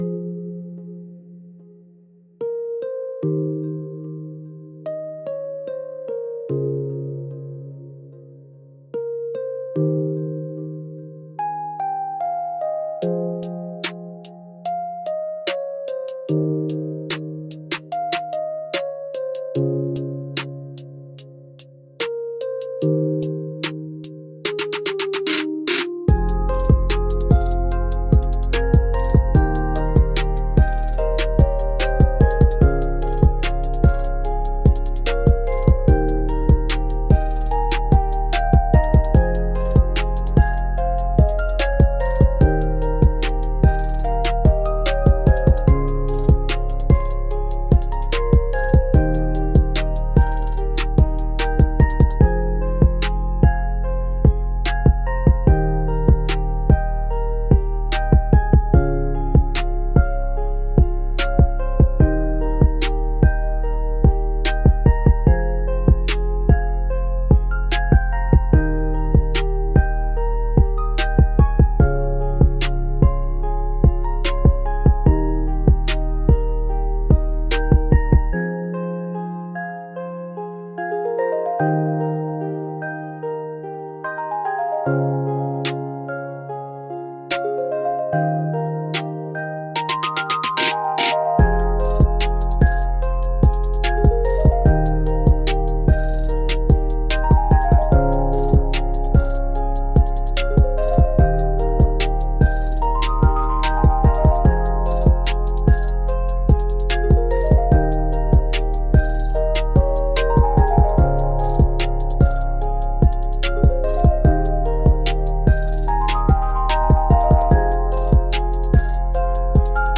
A♭ Major – 147 BPM
Sad
Trap